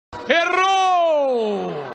O bordão surgiu nos momentos em que um participante do "Domingão do Faustão" falhava em um desafio, especialmente no quadro "Videocassetadas", e o apresentador soltava sua marca registrada com ênfase e humor.
Com a entonação única de Faustão, o "Errou!" segue firme no imaginário popular e é usado até hoje para zoar qualquer erro ou derrota épica.